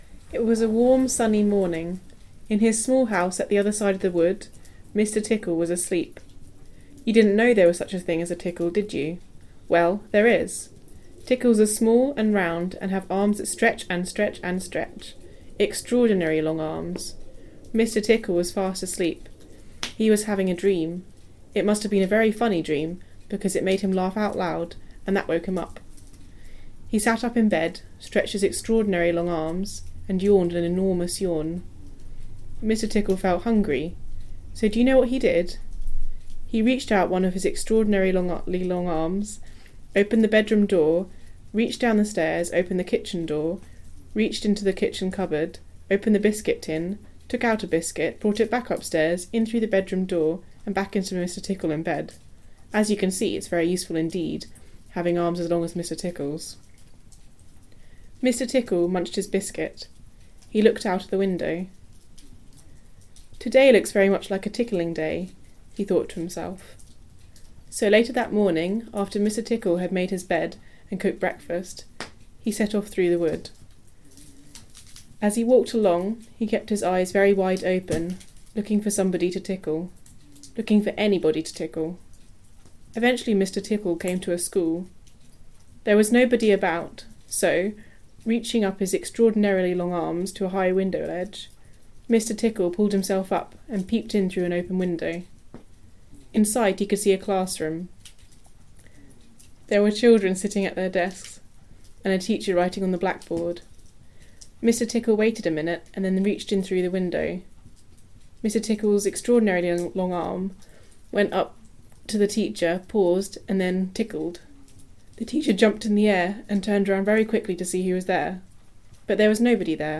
London 1989 female